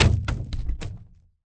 fallingcabbage.ogg